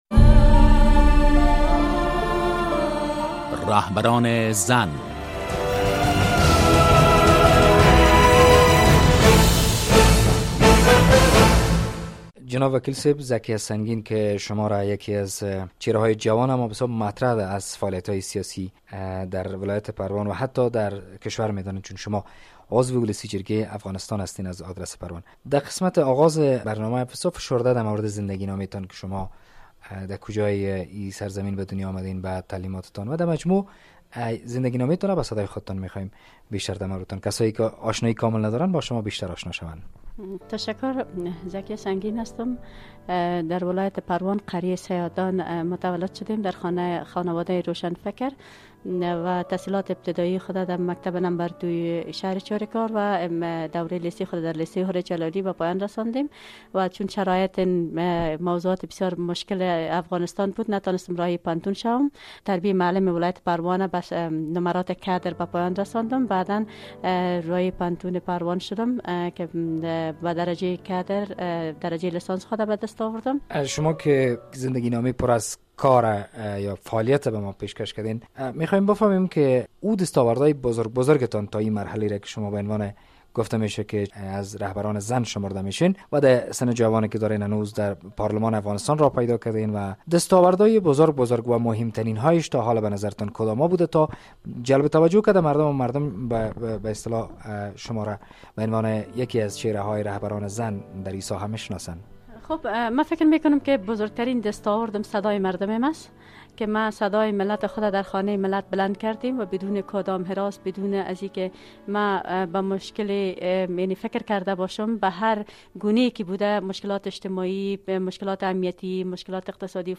ذکیه سنگین وکیل مردم پروان در ولسی جرگۀ افغانستان مهمان این برنامۀ رهبران زن است.